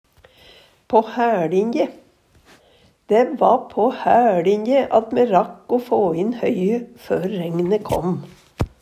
på hæLinje - Numedalsmål (en-US)